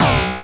sci-fi_weapon_blaster_laser_fun_01.wav